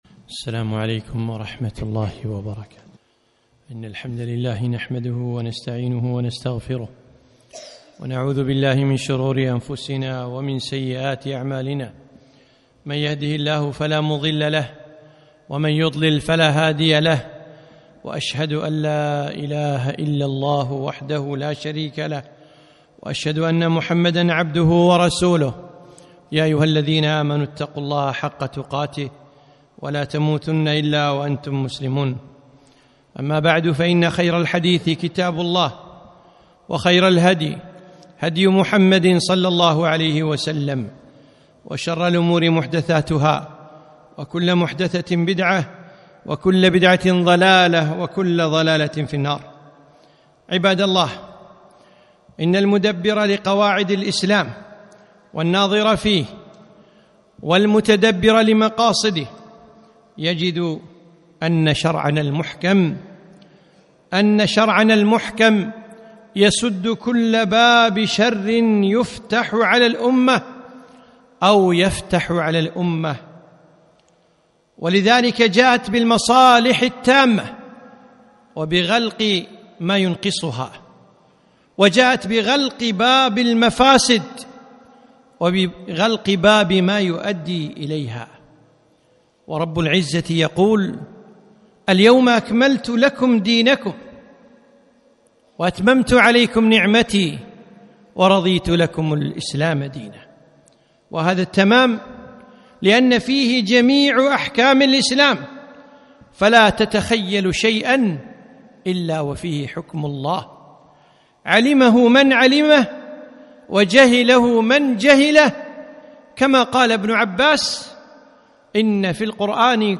خطبة - توقير ولي الأمر